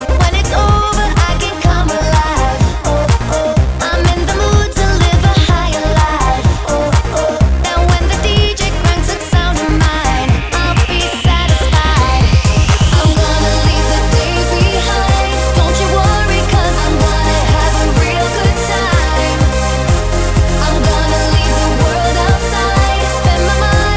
Duet Mix R'n'B / Hip Hop 3:43 Buy £1.50